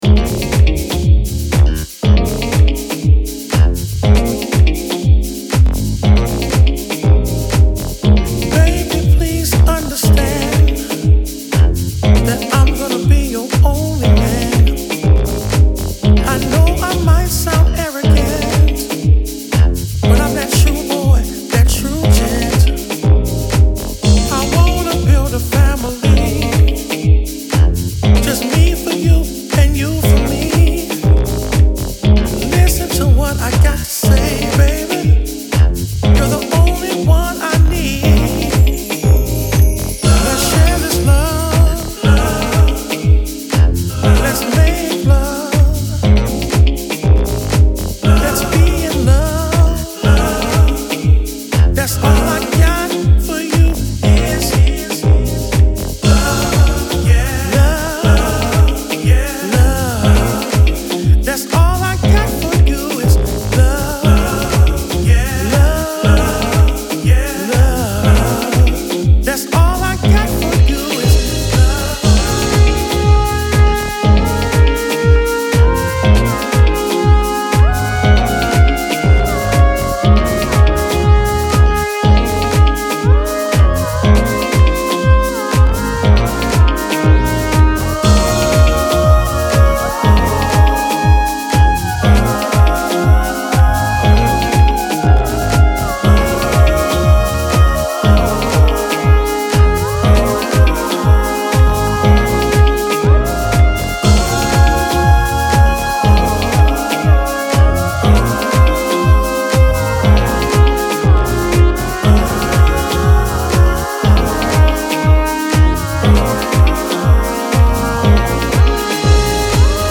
柔らかく弧を描くスペーシー・シンセを配しながらしっとりエモーショナルなヴォーカル・ハウスを展開しています
ディープでムーディーな魅力溢れる一枚に仕上がっています。